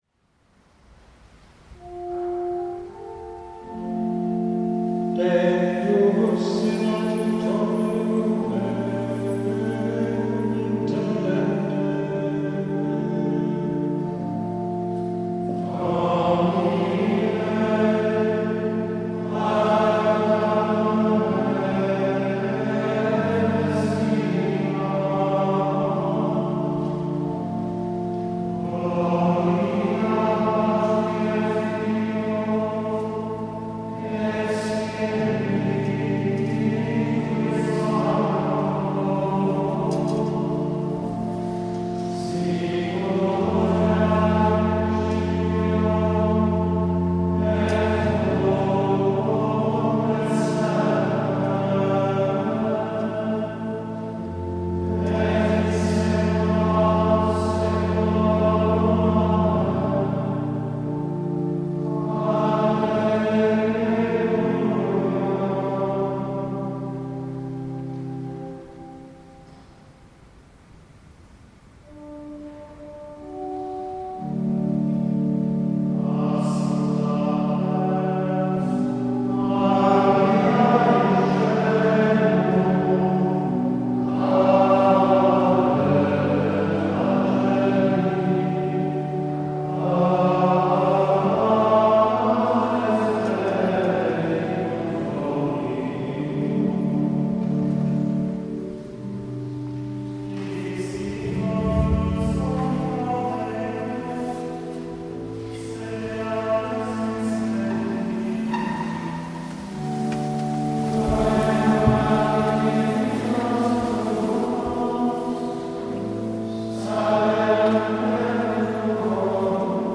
Vespers and Glenstal Abbey